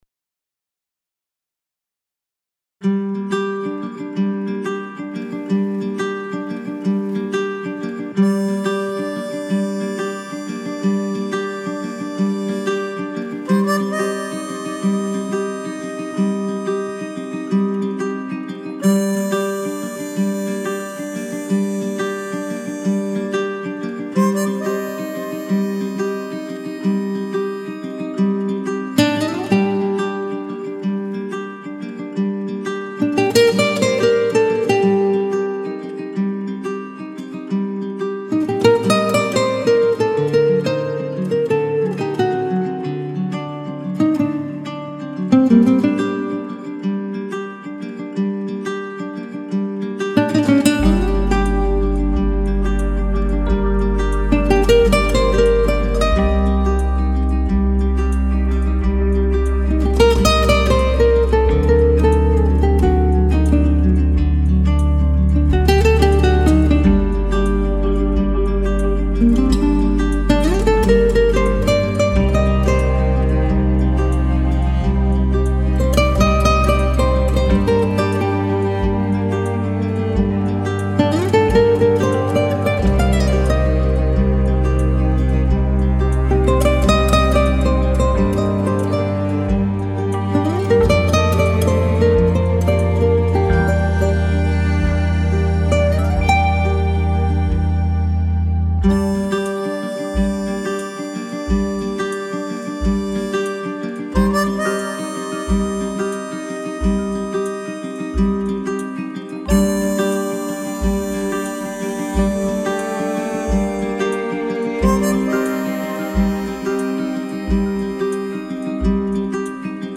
Мелодия эта, но тоже слышен в начале треск.